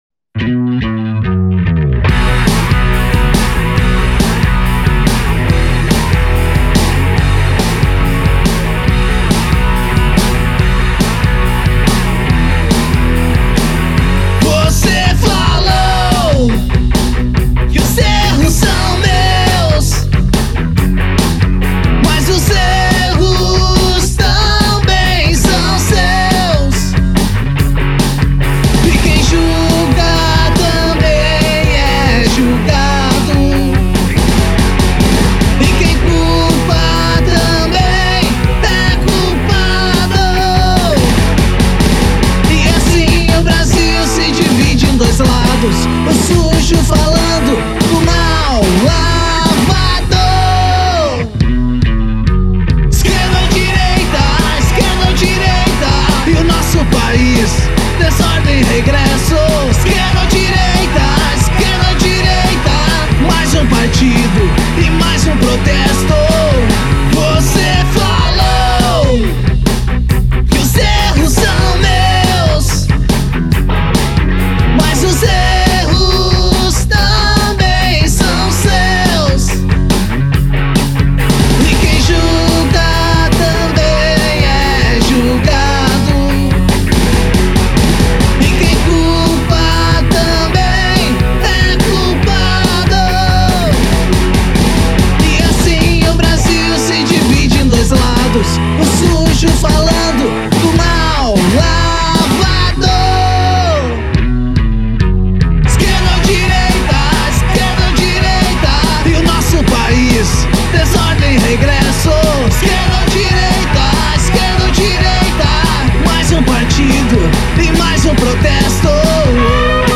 Energia Festa Rock